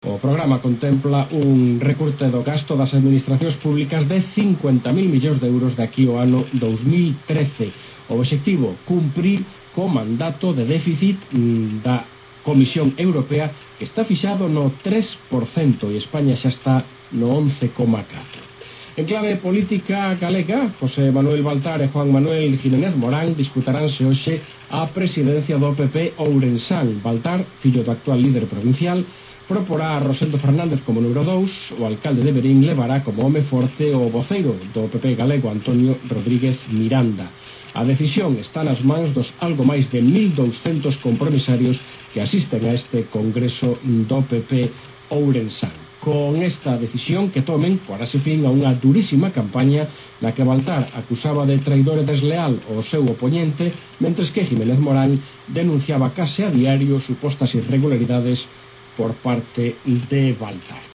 My first guess was Portuguese, but I didn’t hear any nasal vowels.
The announcer even talks about “a política galega”.
I had no idea tht Galician used Castillian style interdentials, but I seem to be hearing them.
I do not know if it’s just bad sound quality, or of this is indeed Galego (“política Galega” gives is away).